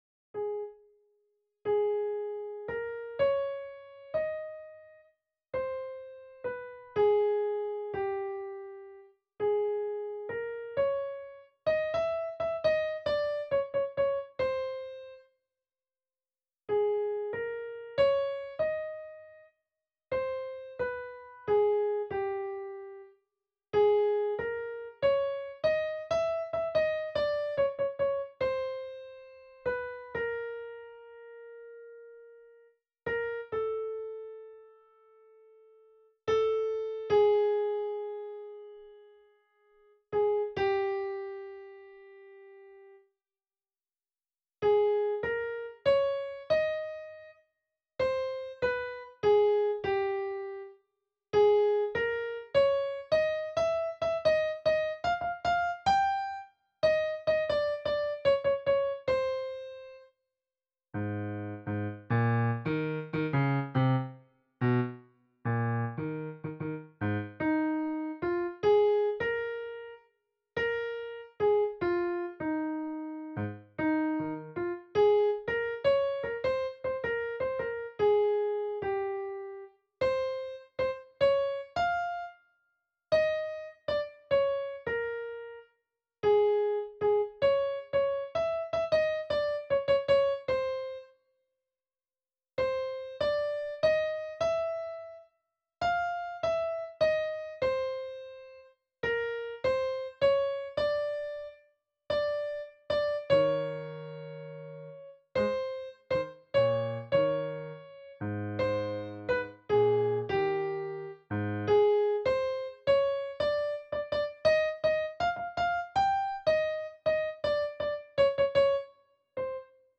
Øvefil for Tenor (wav)
I got rhythm ~ Tenor.WAV